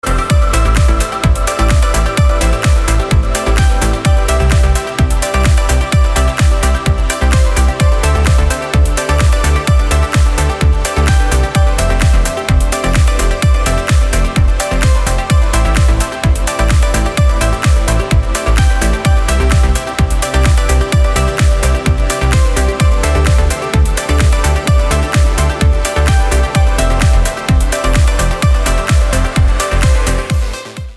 • Качество: 320, Stereo
красивые
dance
без слов
club
Жанр: Trance , Progressive Trance